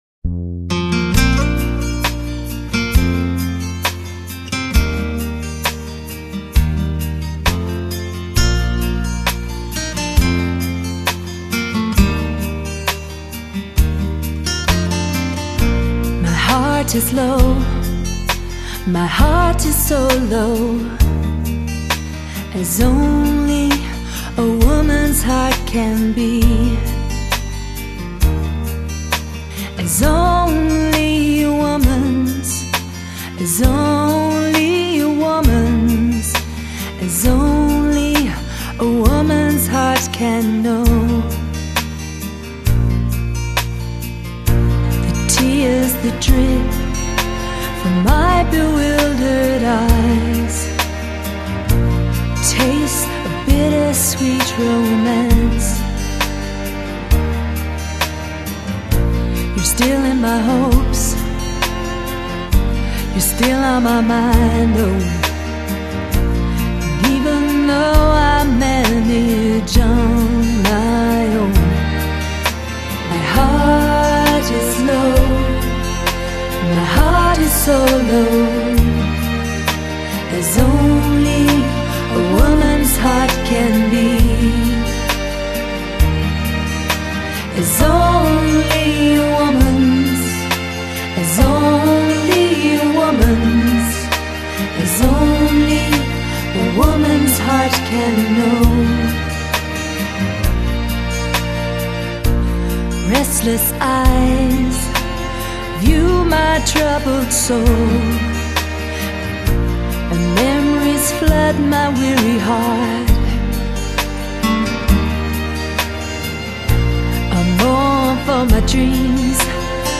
充满德国“谜”般的乐风与“森林物语”的大自然愉悦观，传递神秘的雨林音乐观